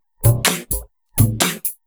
Index of /VEE/VEE2 Loops 128BPM
VEE2 Electro Loop 223.wav